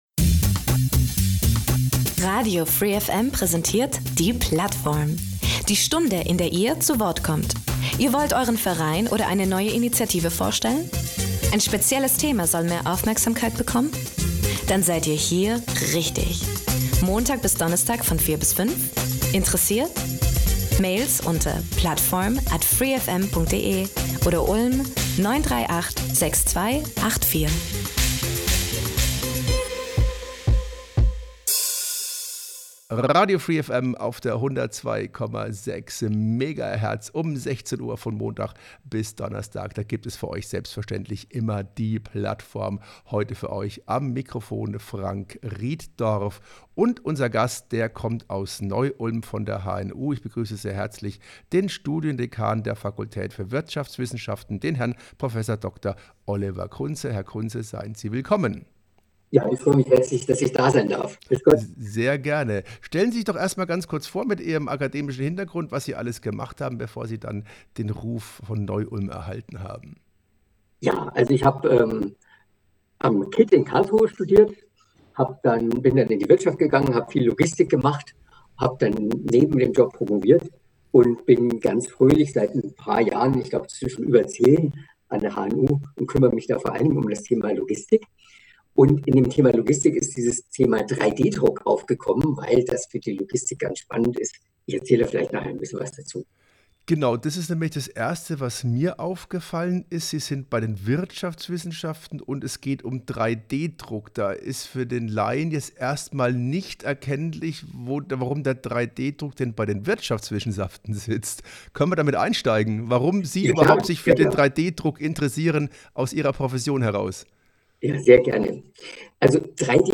Am 14. Juli hat Radio free FM live vom Allerweltsfest-Sommerfest aus dem Roxy gesendet.